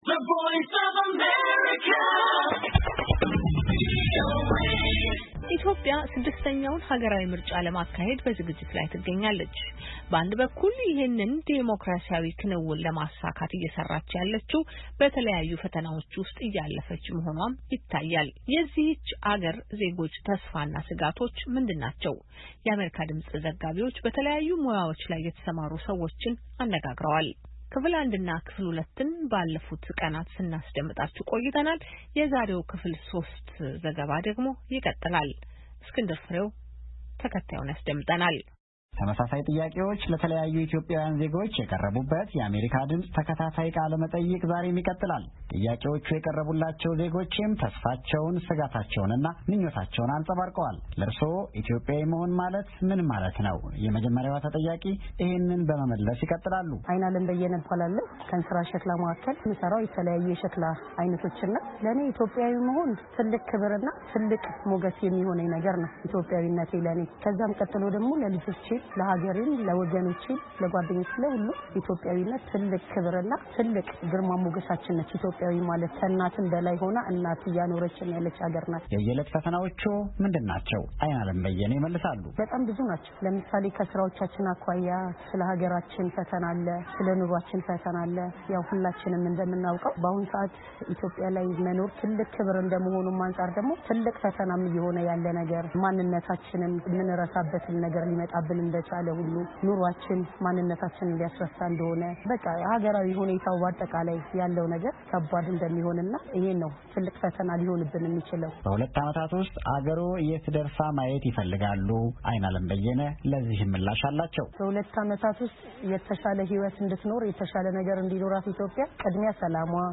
የአሜሪካ ድምጽ ዘጋቢዎች በተለያዩ ሙያዎች ላይ የተሰማሩ ዜጎችን አነጋግረዋል።በዘላቂነት ለማቋቋም ከ120 ሚሊዮን ብር ያላነሰ ያስፈልጋል ተብሏል፡፡